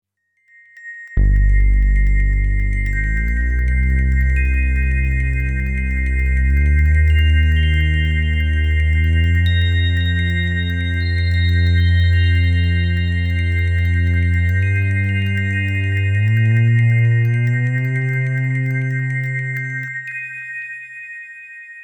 bas_en_plings.mp3